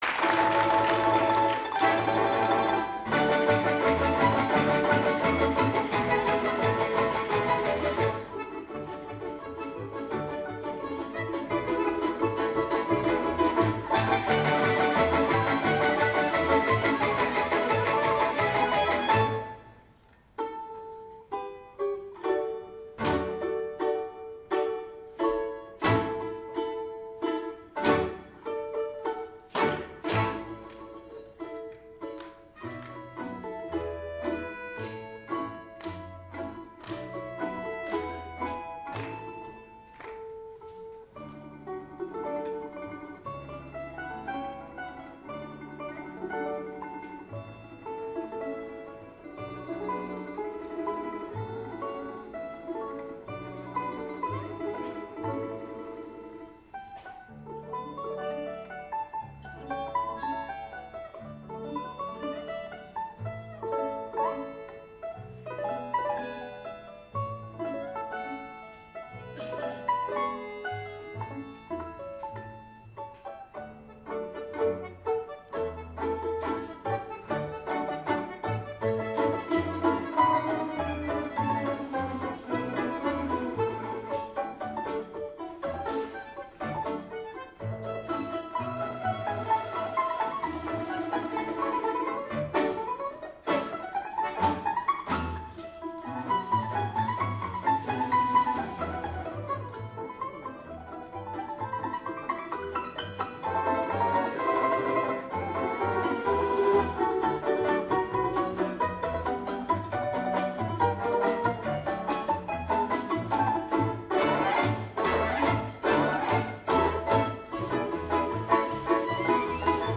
Балалайка-оркестр_(Германия_2008)_-_Ах_вы_сени__мои_сени